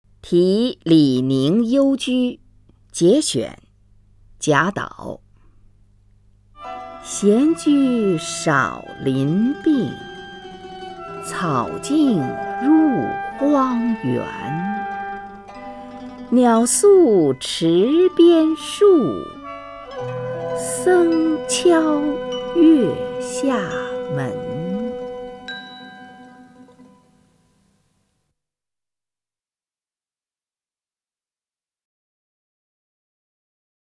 虹云朗诵：《题李凝幽居（节选）》(（唐）贾岛) （唐）贾岛 名家朗诵欣赏虹云 语文PLUS